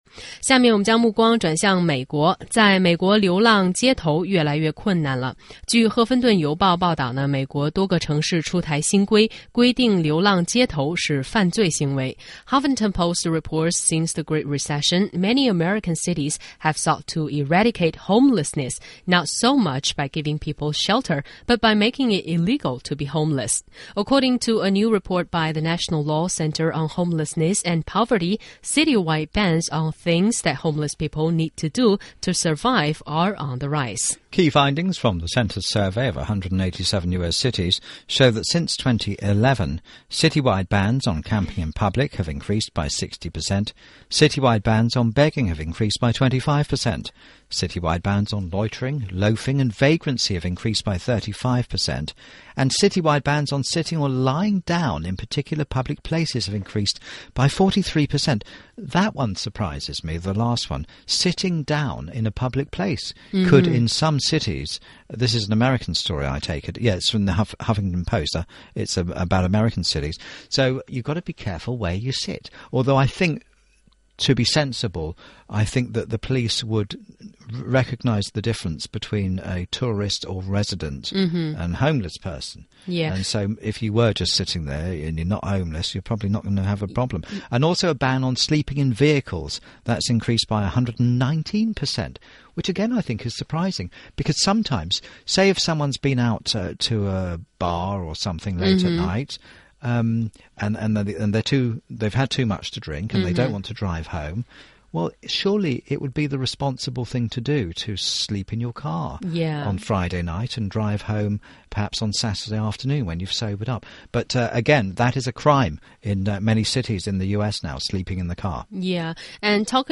中英双语的音频，能够帮助提高英语学习者的英语听说水平，中外主持人的地道发音，是可供模仿的最好的英语学习材料,可以帮助英语学习者在轻松娱乐的氛围中逐渐提高英语学习水平。